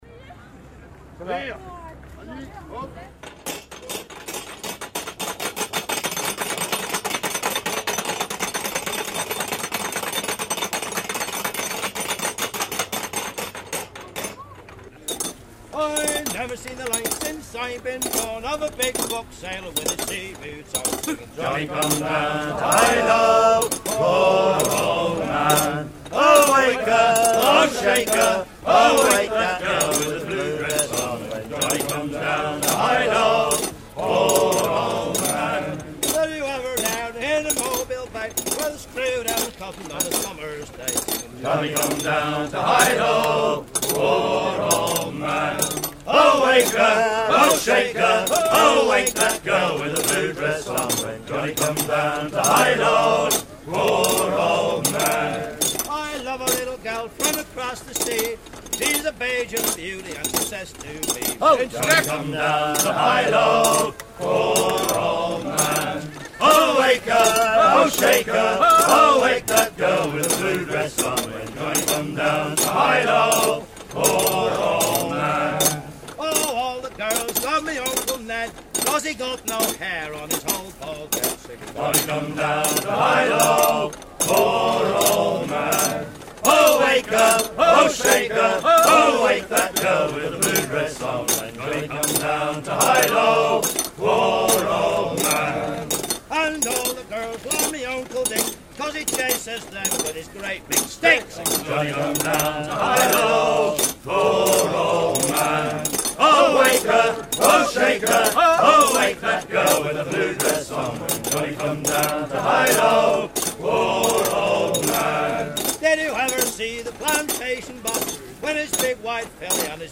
à virer au cabestan
Genre laisse
Pièce musicale éditée